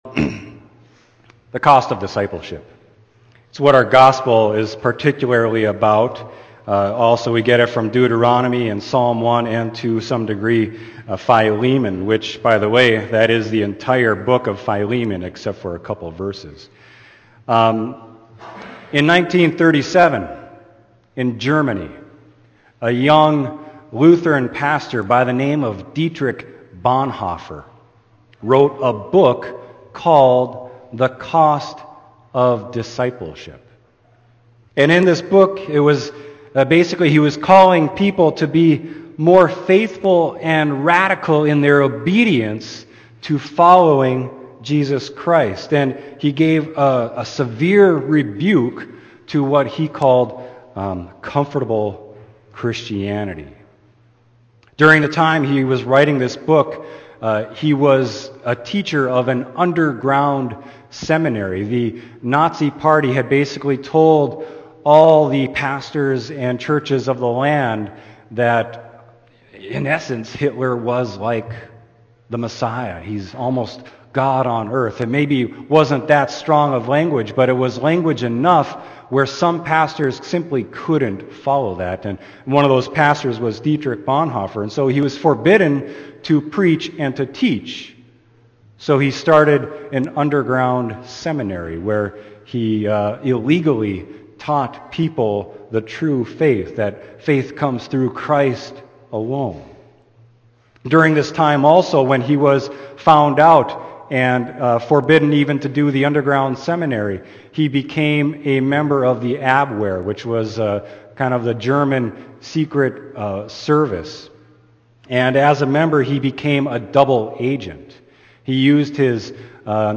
Sermon: Luke 14.25-33